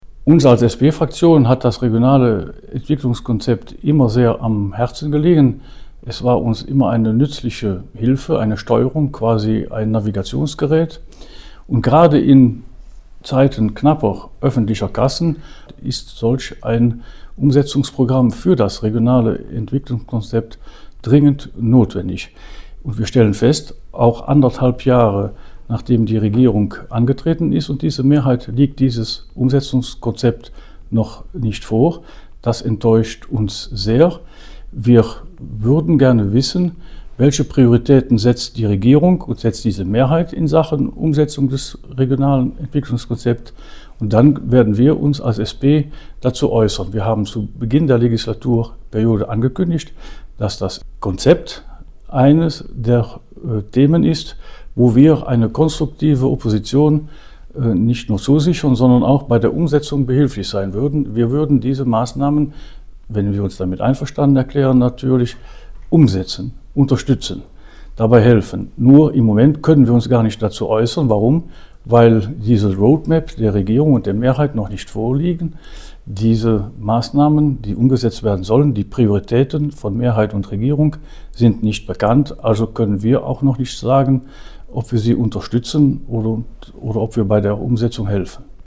Auch das Regionale Entwicklungskonzept liegt der SP am Herzen wie der PDG-Abgeordnete Charles Servaty erklärt.